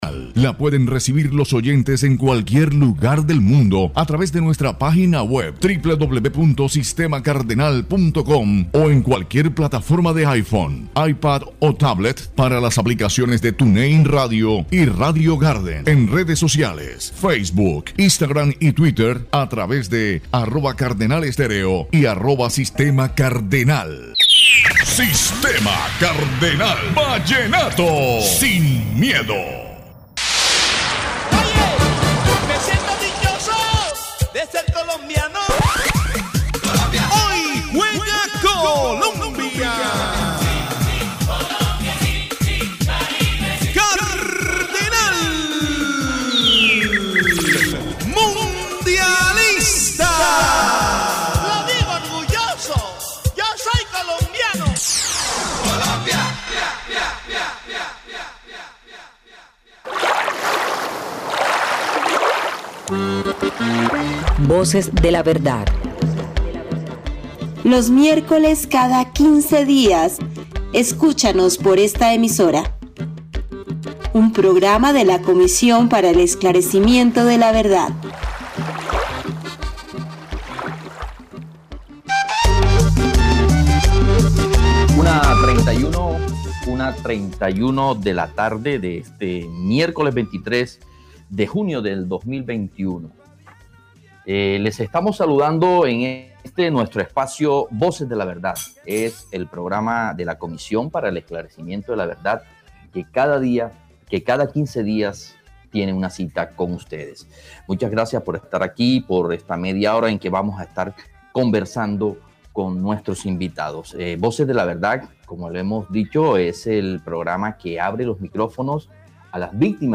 Escucha en Diario del Norte la emisión del programa Voces de la Verdad del 07 de julio, emitido por Sistema Cardenal 1360 AM Cartagena.